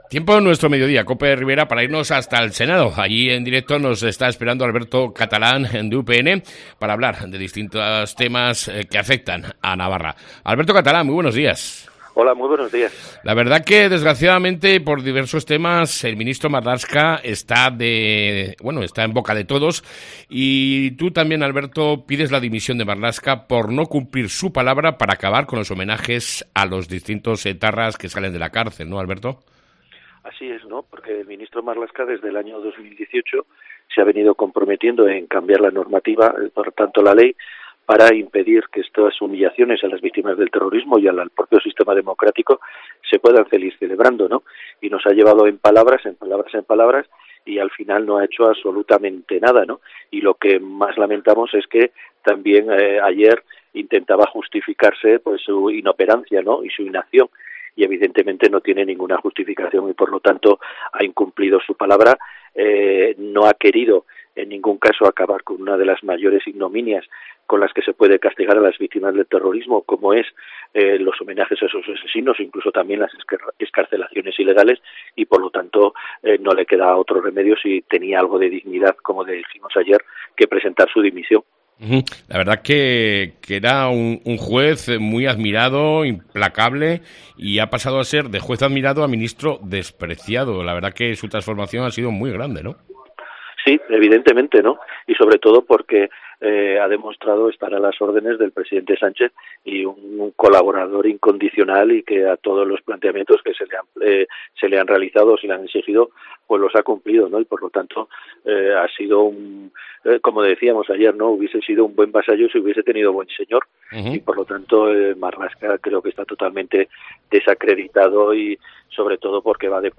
ENTREVISTA CON EL SENADOR DE UPN ALBERTO CATALÁN